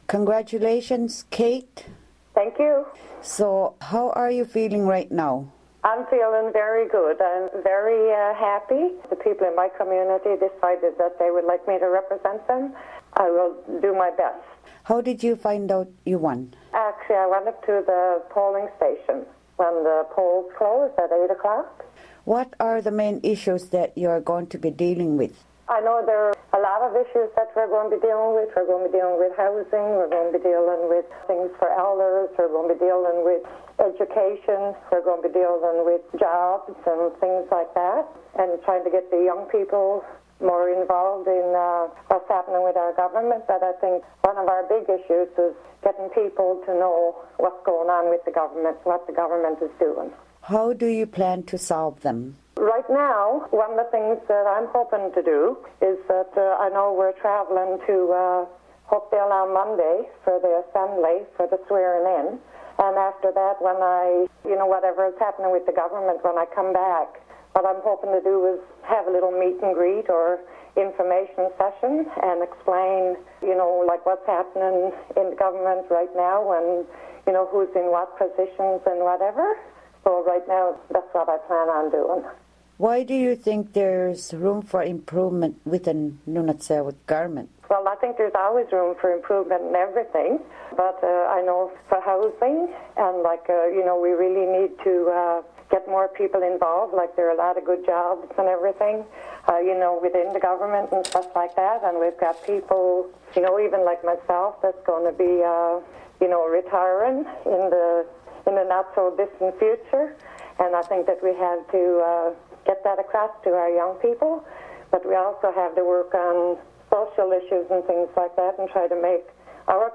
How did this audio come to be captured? OKâlaKatiget Radio will be continuing to speak with newly elected Ordinary Members of the Nunatsiavut Assembly.